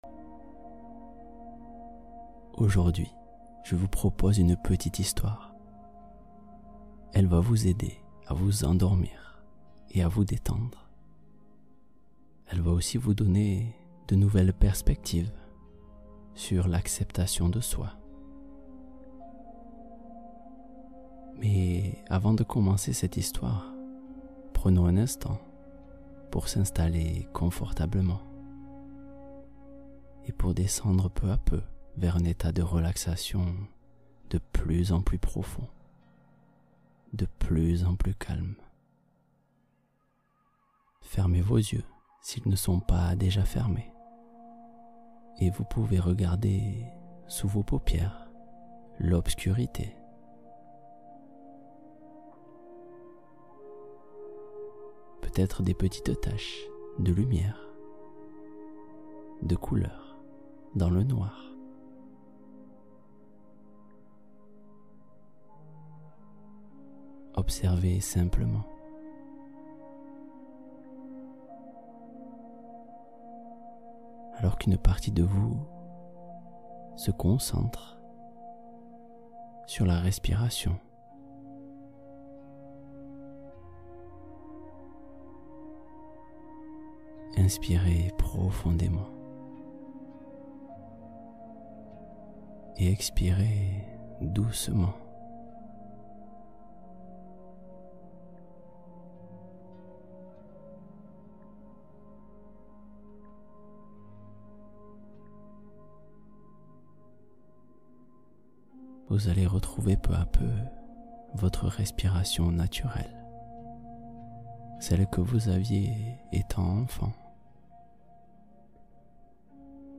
Histoire pour s’endormir : Le Porteur d’Eau et l’acceptation de soi